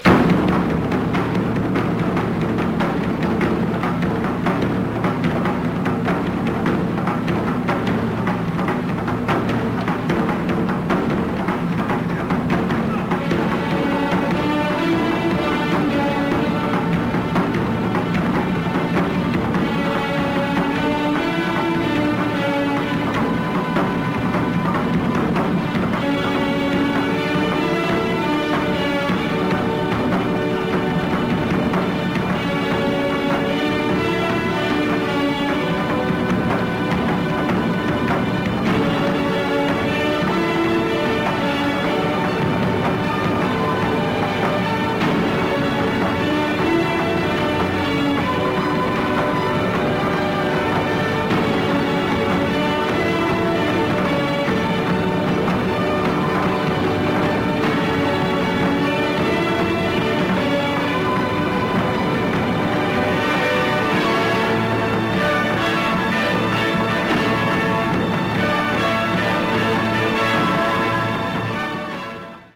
Japanese percussion